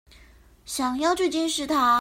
Тайваньский 430